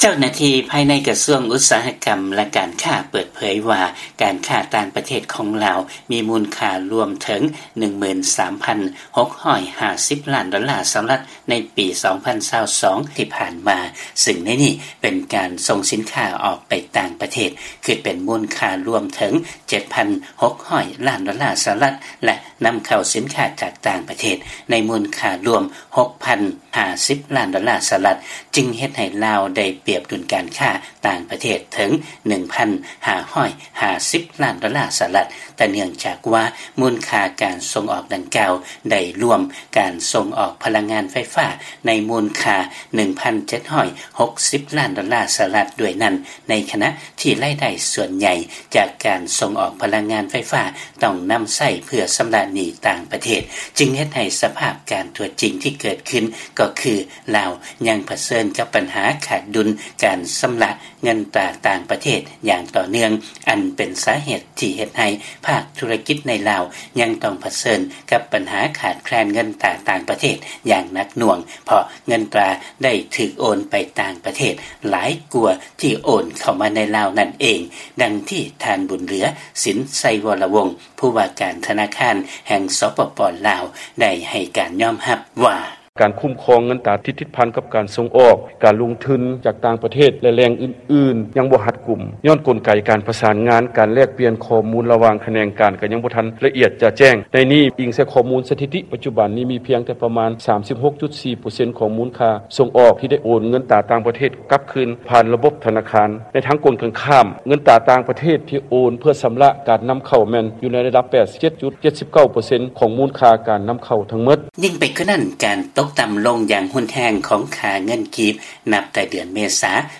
ຟັງລາຍງານ ປະຊາຊົນລາວ ຍັງຕ້ອງປະເຊີນກັບພາວະຄ່າຄອງຊີບສູງຕໍ່ໄປ ເນື່ອງຈາກການຂາດດຸນການຄ້າ ແລະ ຂາດແຄນເງິນຕາຕ່າງປະເທດ